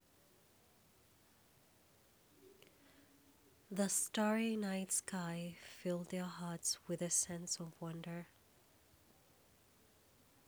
sad.wav